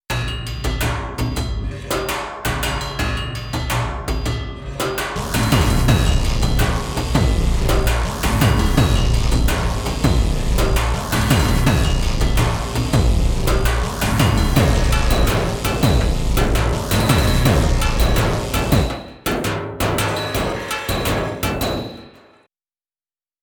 Currently available: Samples from Syntrx II, Blast Beats, Sounds for Syntakt, Digitone and Hydrasynth, Samples from a Finnish scrapyard and a Swiss crematorium.
Combined with a bass and a kick from “Voltage Chaos” (comes in at 00:05), it sounds like this: